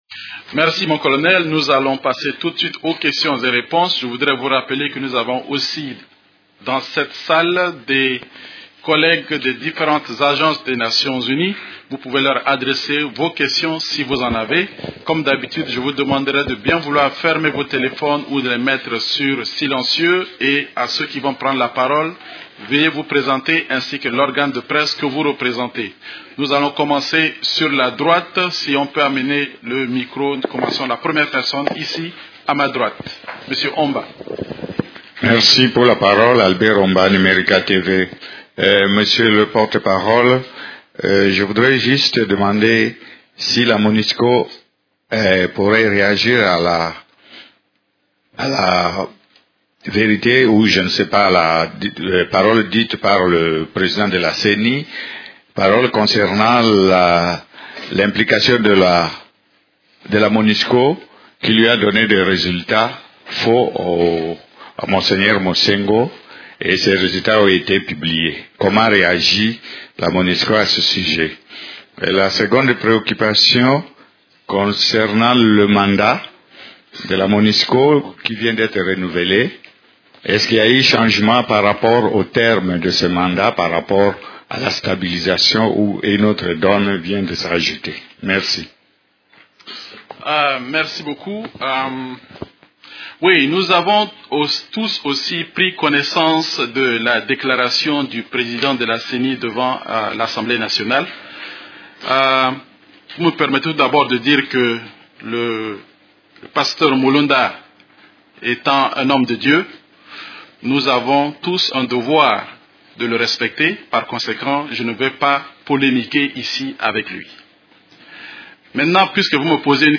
Conférence du 4 juillet 2012